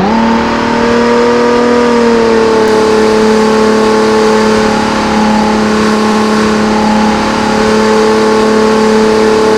supra_revdown.wav